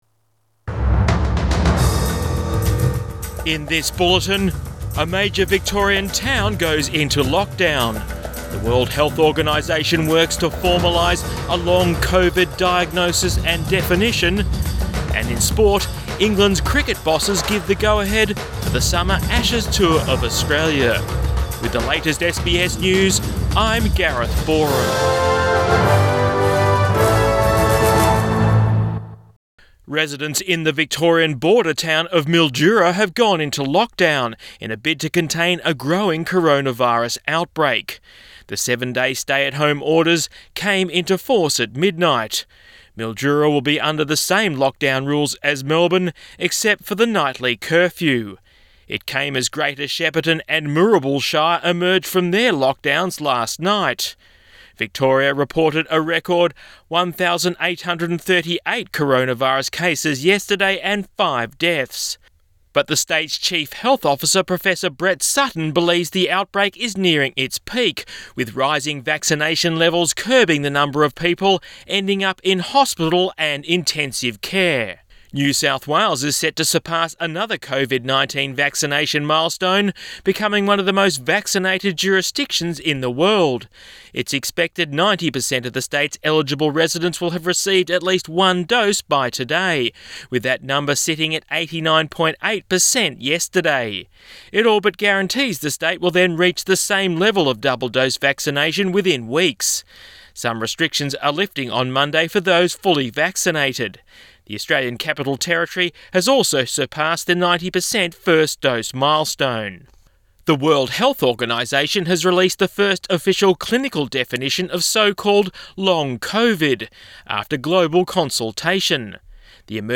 0800 bulletin 9 October 2021